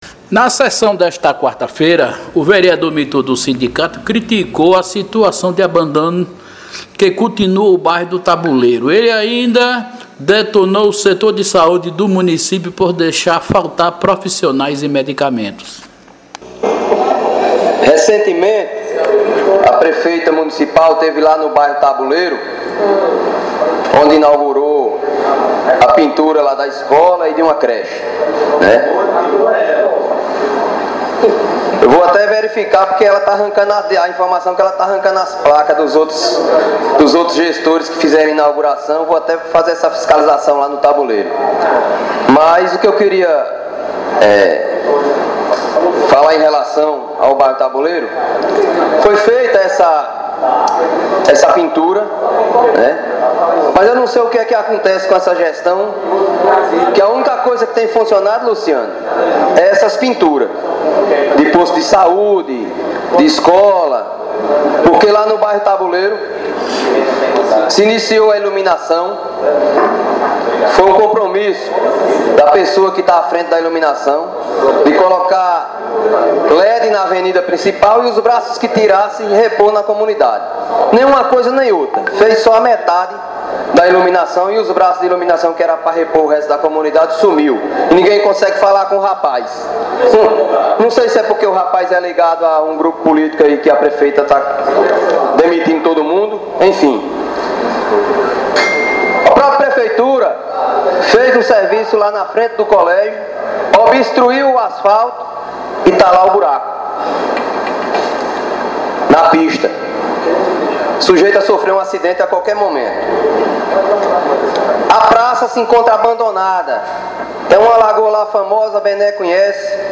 A sessão na Câmara de Juazeiro nesta quarta-feira foi bastante agitada mais uma vez. O vereador Mitu do Sindicato (PCdoB) criticou a situação de abandono que continua no bairro Taboleiro.